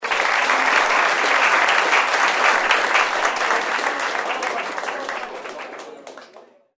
Efectos de sonido
APLAUSOSFUERTES APLAUSOS 2
Ambient sound effects
aplausosfuertes_aplausos_2.mp3